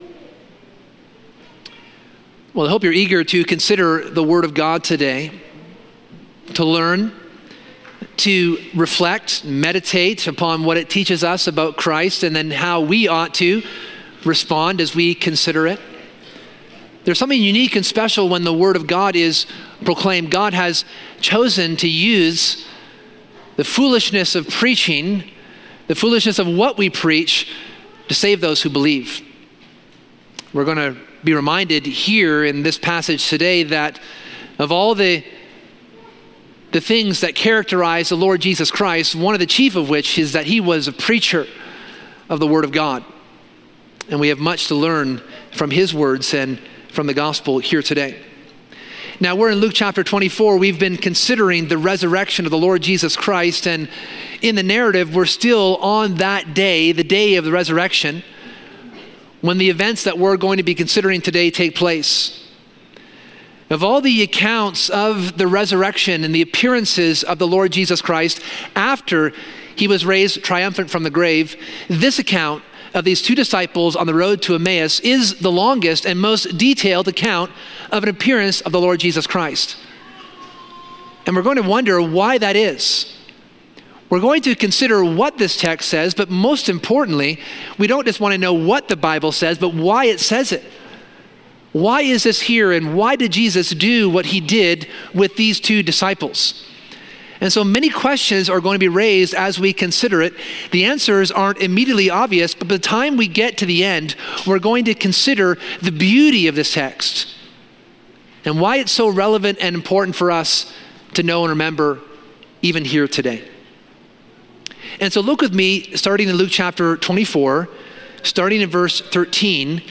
This sermon explores the transformative encounter of two disciples with the risen Jesus on the road to Emmaus.